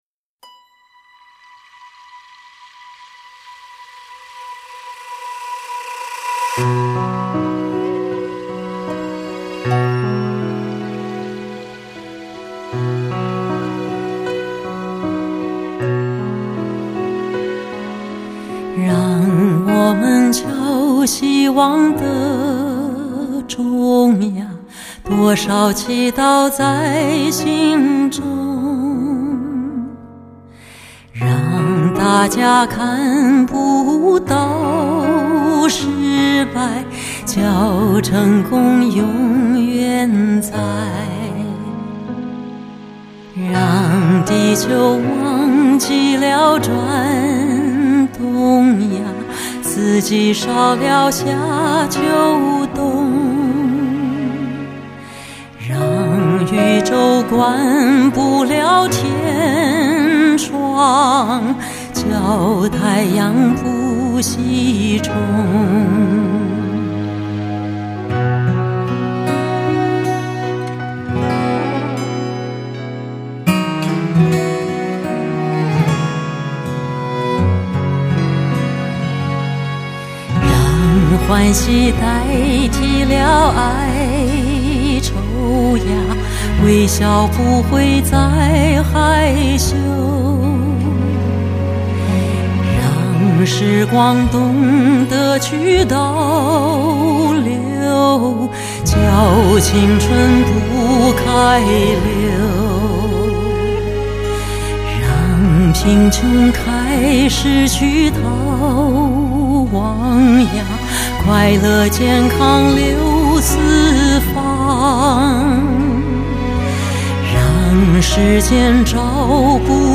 母带处理：日本天龙DENON录音合成